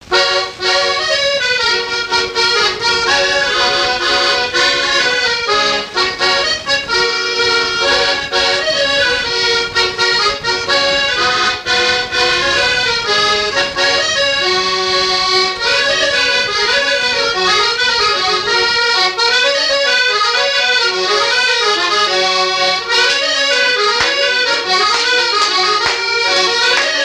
Mémoires et Patrimoines vivants - RaddO est une base de données d'archives iconographiques et sonores.
danse
Pièce musicale inédite